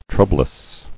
[trou·blous]